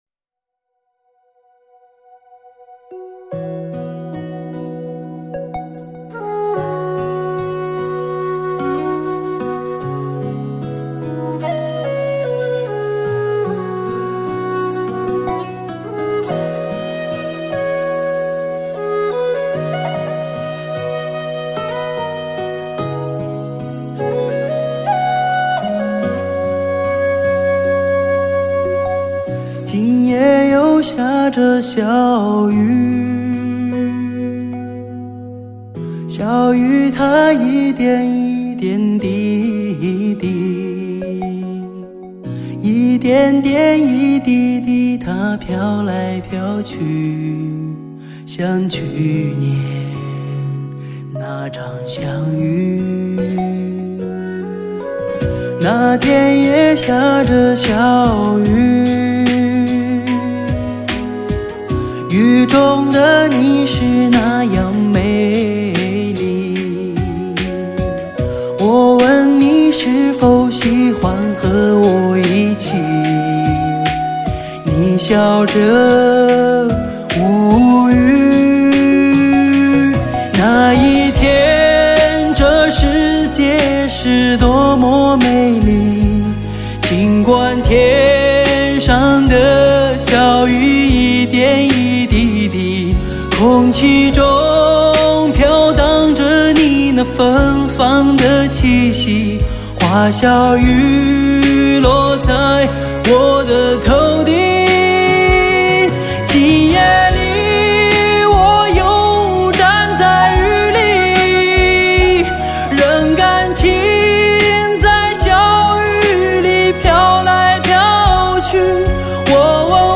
音乐类型: 天籁人声/POP
音效 超震撼 最佳现场感
真实而不虚饰的发烧人声，营造浪漫柔美的音乐境界！